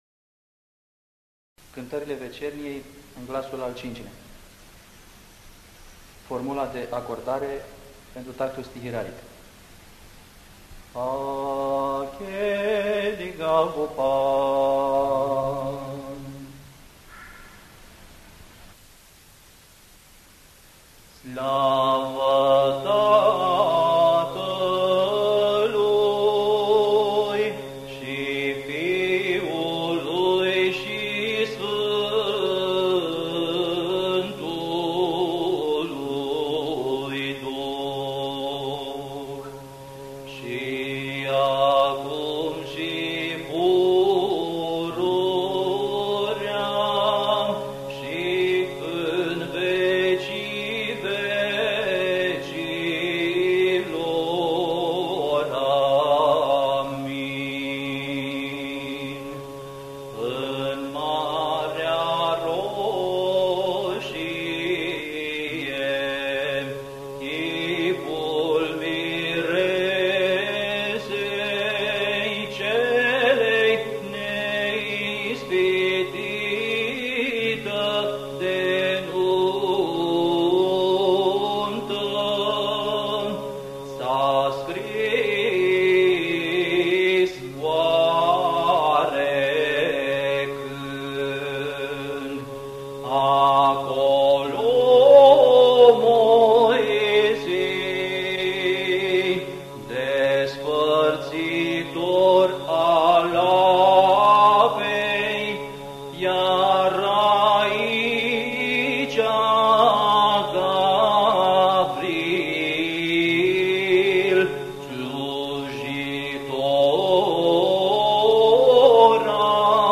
Index of /INVATAMANT/Facultate Teologie pastorala/Muzică bisericească și ritual/Dogmaticile
05. Dogmatica glasul 5.mp3